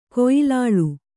♪ koyilāḷu